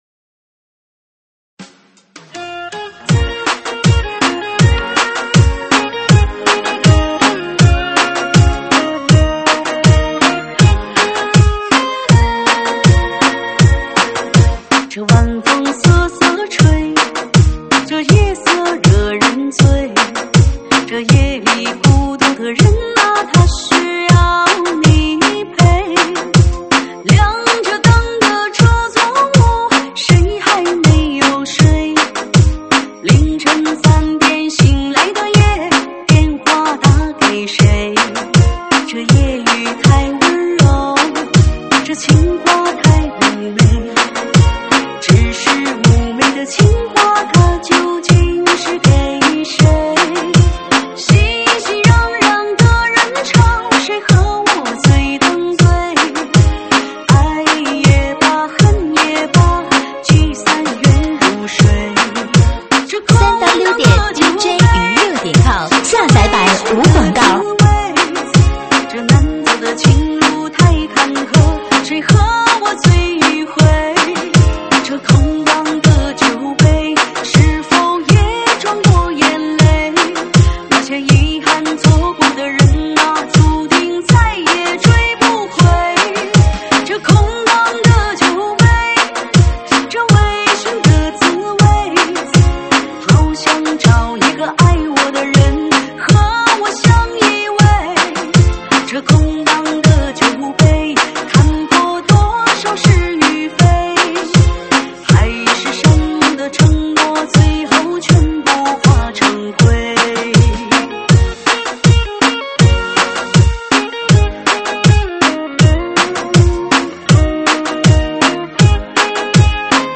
舞曲类别：水兵舞